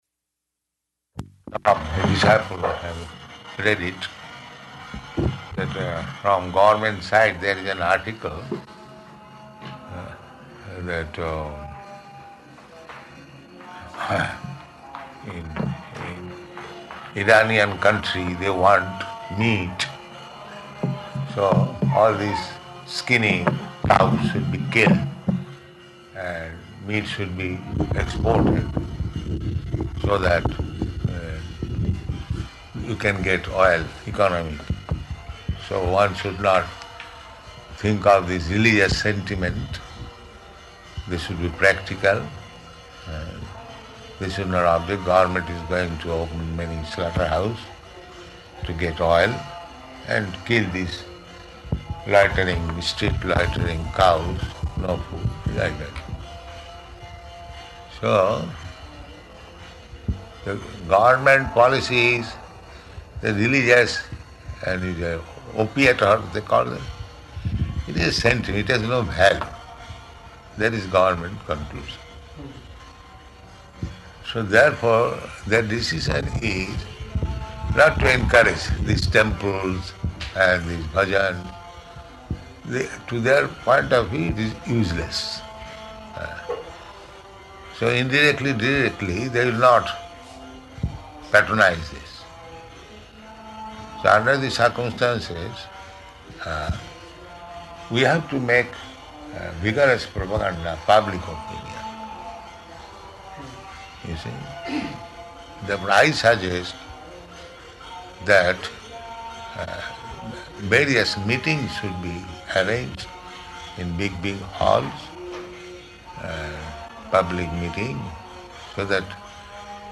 Room Conversation
Type: Conversation